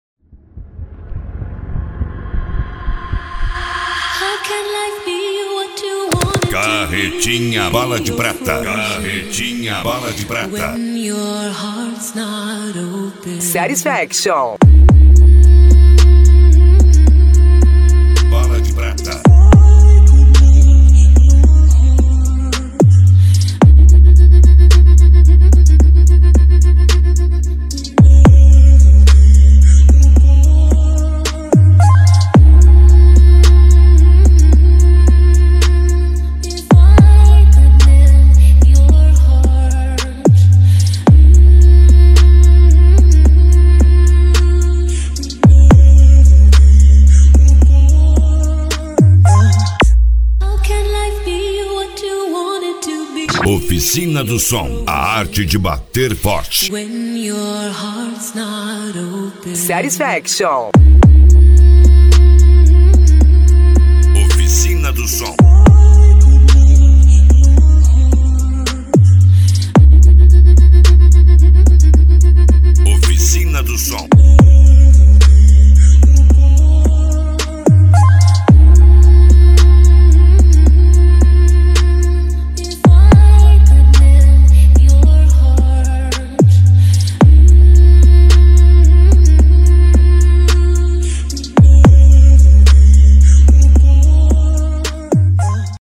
Deep House
Remix
SERTANEJO
Sertanejo Universitario